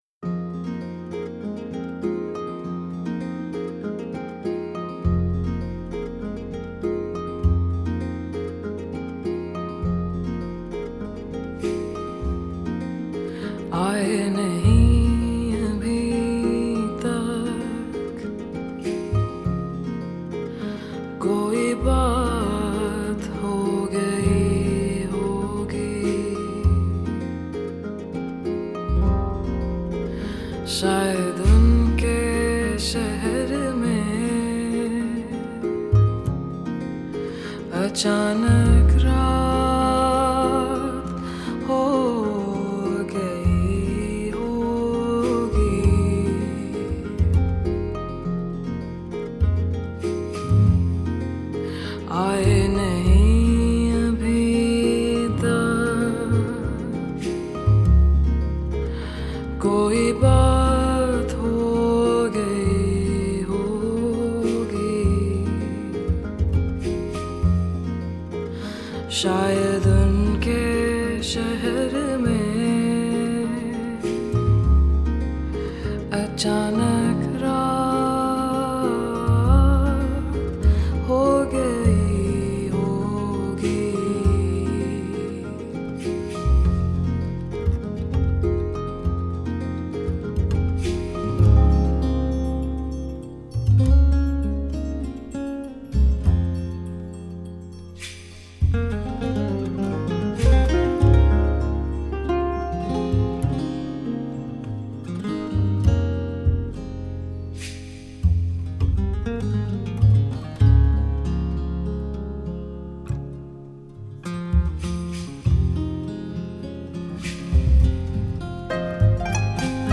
chiacchierata